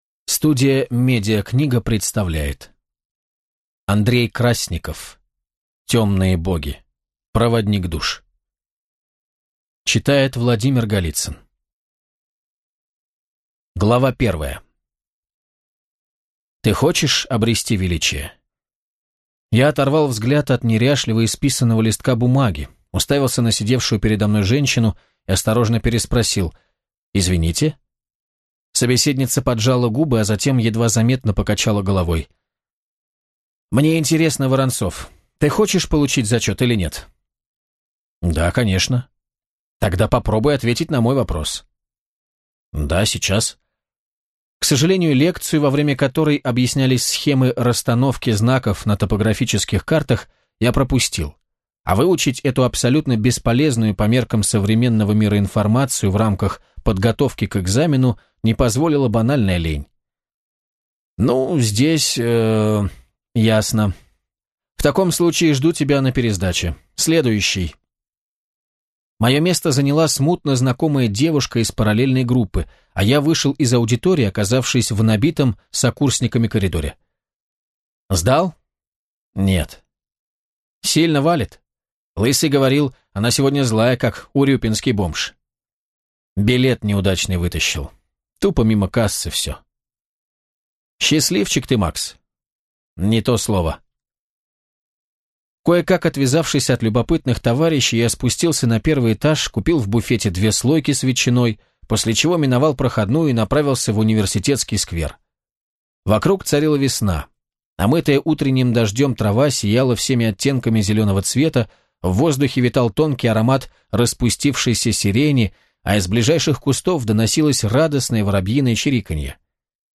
Аудиокнига Темные боги. Проводник душ | Библиотека аудиокниг